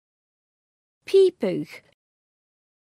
Amazon AWS (pronunciation).